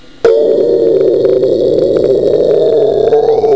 wind2.mp3